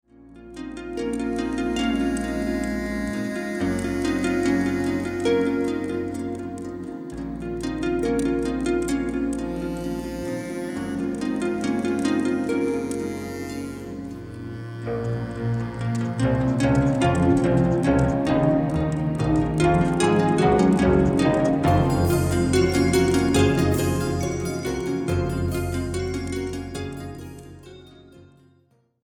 Recorded spring 1986 at the Sinus Studios, Bern Switzerland